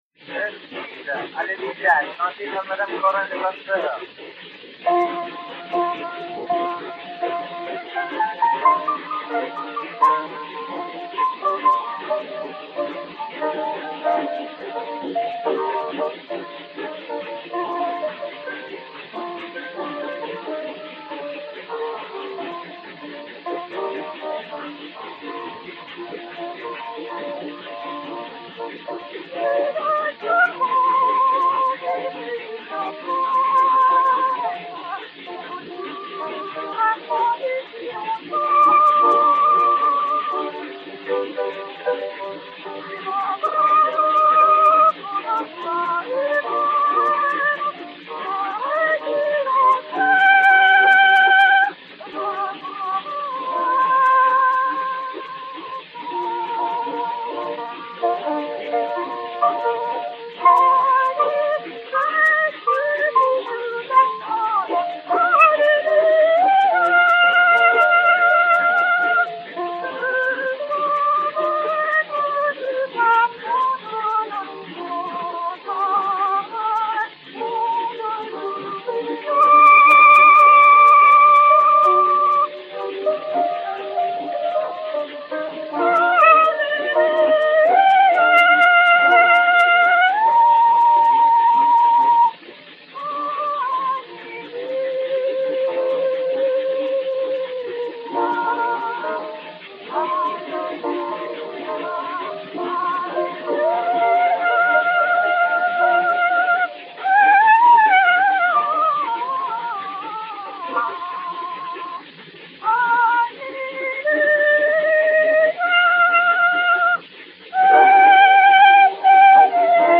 et Piano
cylindre Bettini n° 14, enr. à Paris en 1900/1901